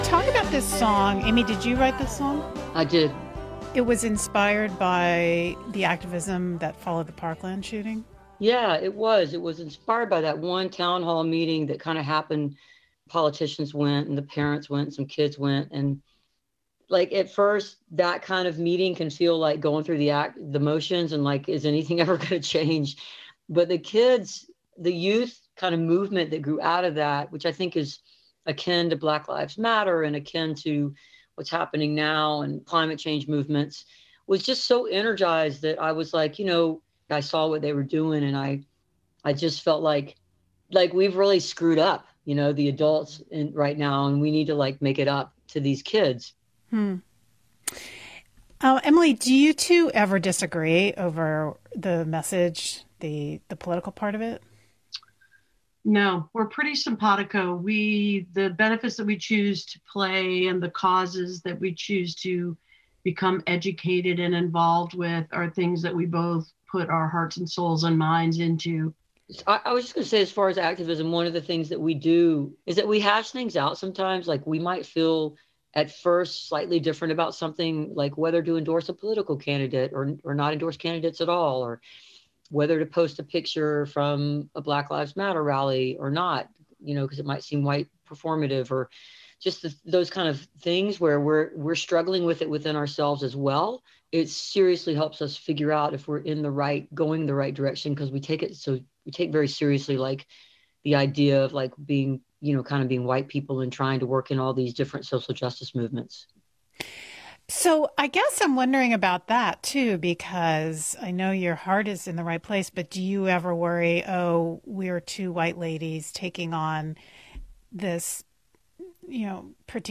04. interview (5:06)